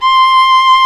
Index of /90_sSampleCDs/Roland L-CD702/VOL-1/STR_Viola Solo/STR_Vla1 % + dyn